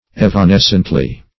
Search Result for " evanescently" : The Collaborative International Dictionary of English v.0.48: Evanescently \Ev`a*nes"cent*ly\, adv. In a vanishing manner; imperceptibly.